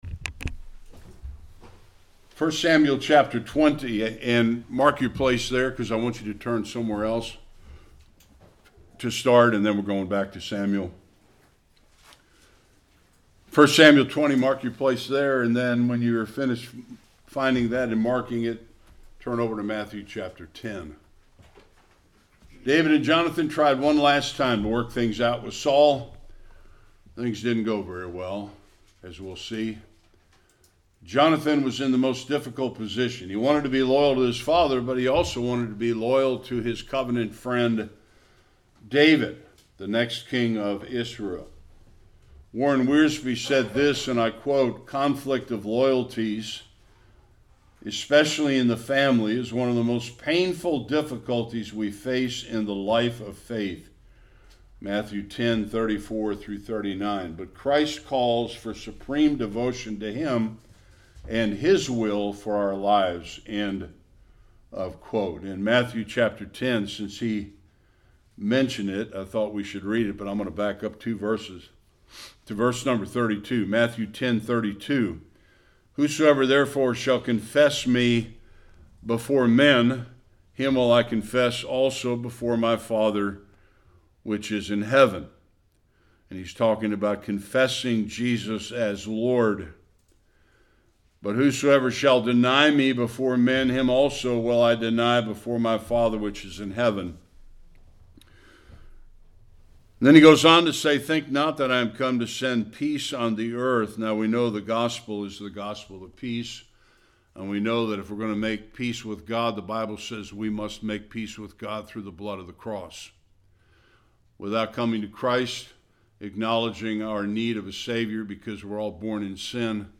1-42 Service Type: Sunday School David and Jonathan try one more time to reason with King Saul.